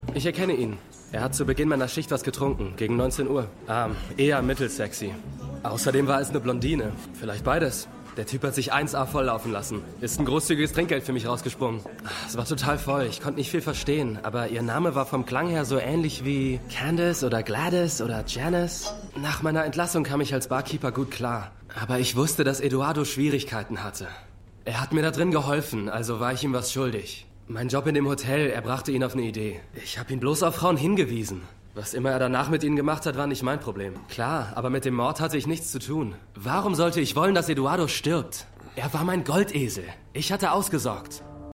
Synchronstudio: Arena-Synchron [Berlin]